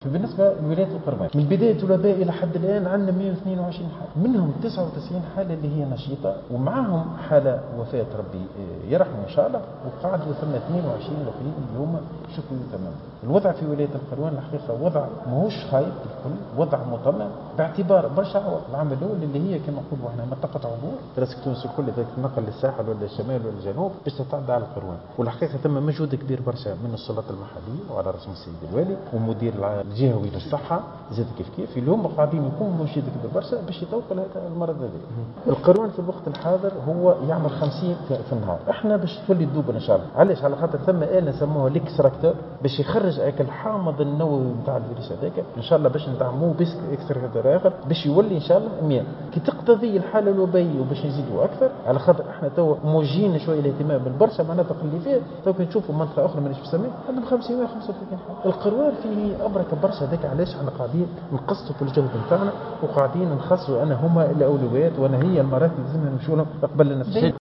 وأضاف في تصريح لمراسل "الجوهرة أف أم" أن الوضع في الجهة مطمئن وذلك بفضل المجهودات المبذولة من قبل السلط المحلية لتطويق المرض.